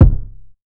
Driven Kick.wav